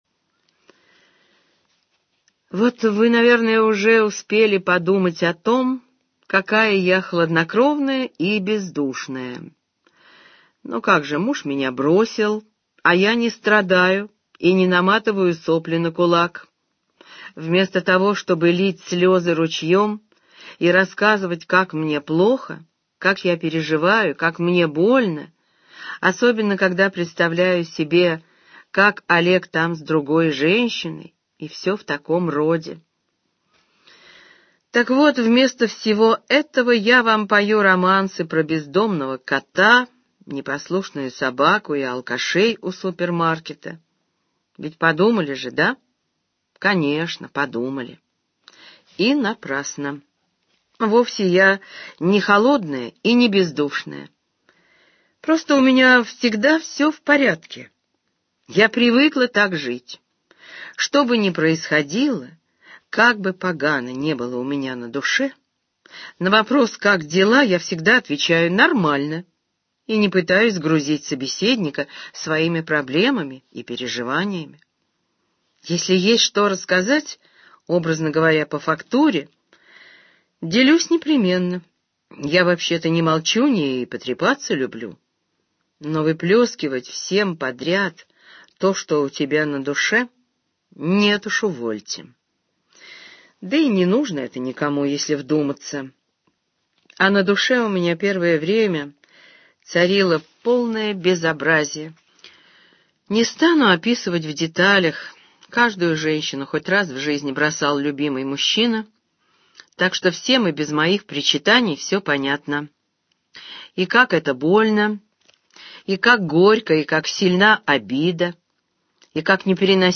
Аудиокнига Каждый за себя - купить, скачать и слушать онлайн | КнигоПоиск